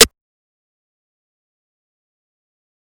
Snare [Hi Roller].wav